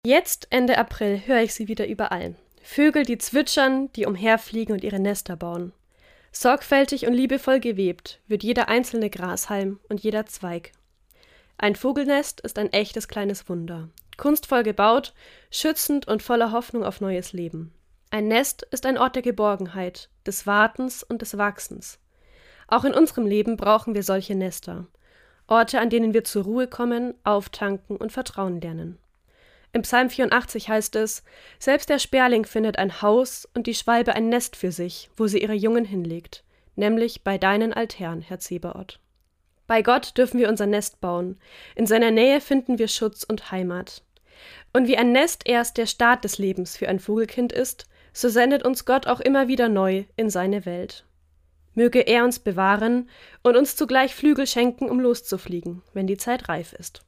Autorin und Sprecherin